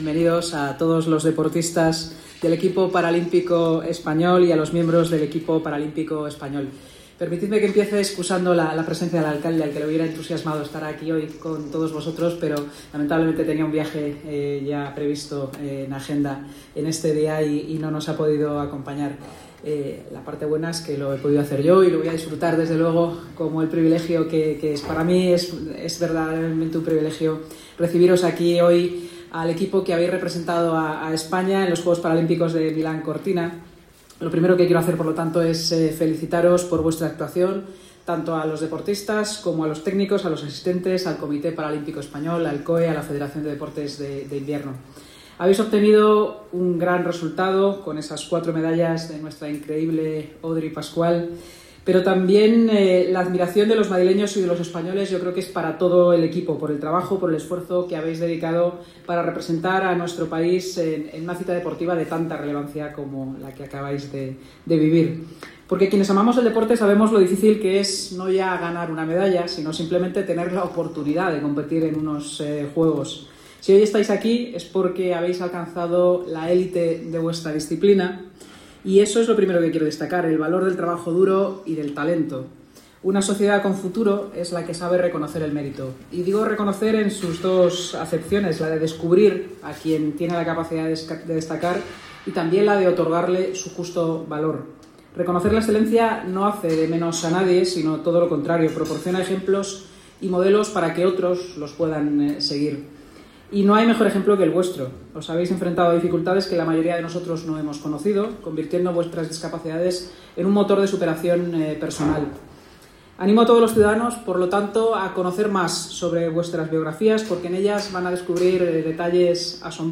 AUDIO-Sanz-preside-la-recepcion-institucional-al-Comite-Paralimpico-Espanol-.mp3